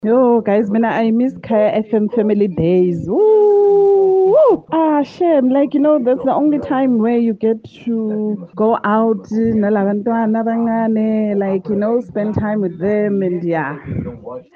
These are was an overwhelming number of listeners who really miss going to super-spreader events such as festivals, big stadium events.